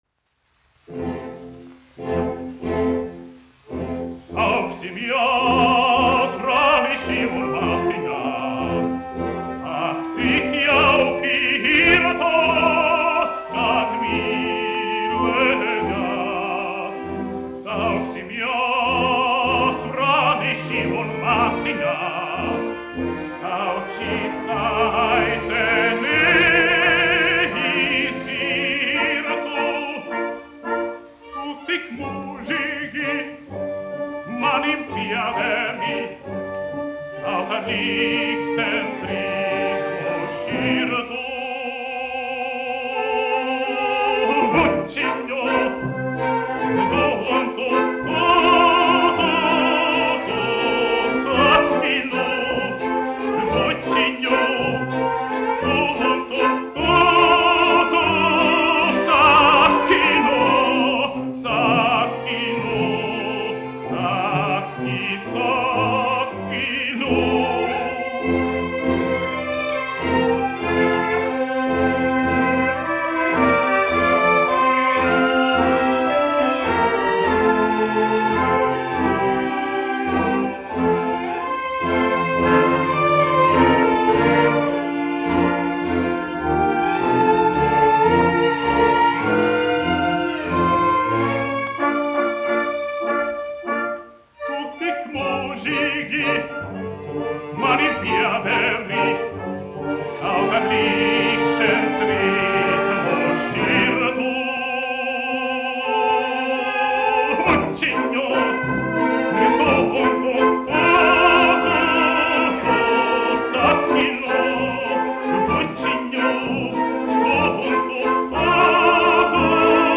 1 skpl. : analogs, 78 apgr/min, mono ; 25 cm
Operas--Fragmenti
Skaņuplate
Latvijas vēsturiskie šellaka skaņuplašu ieraksti (Kolekcija)